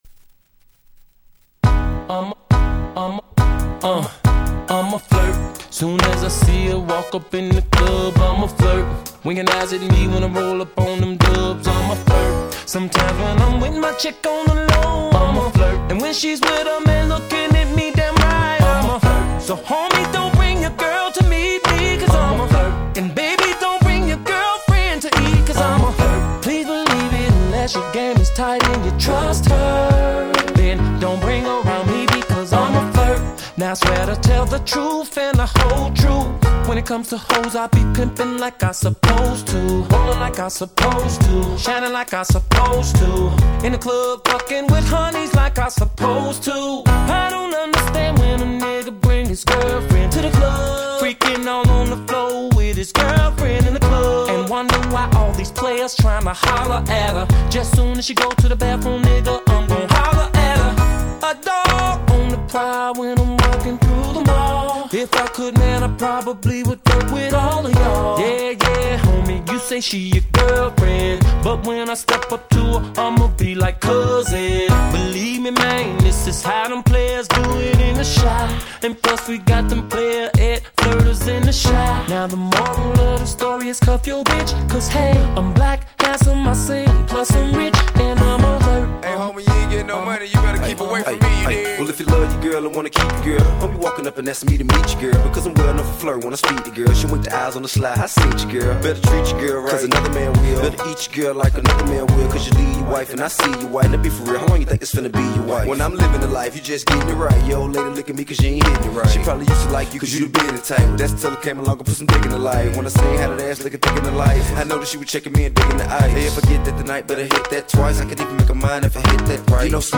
07' Big Hit R&B !!